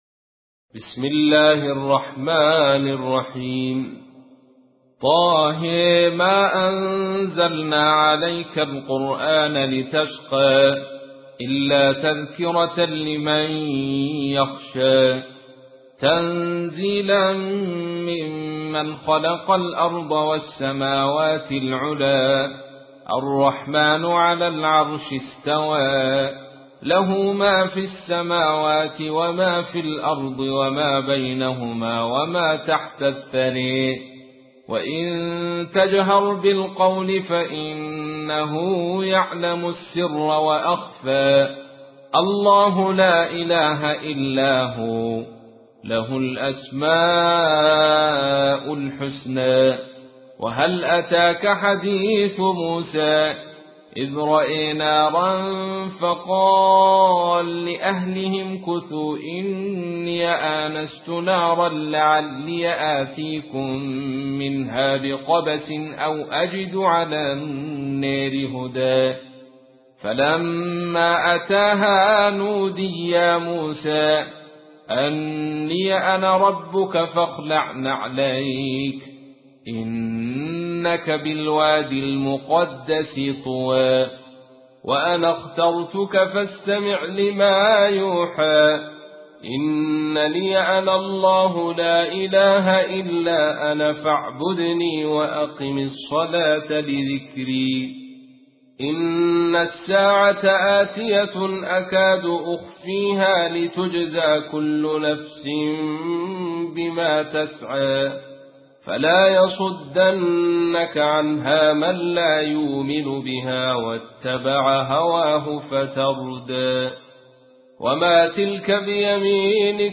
تحميل : 20. سورة طه / القارئ عبد الرشيد صوفي / القرآن الكريم / موقع يا حسين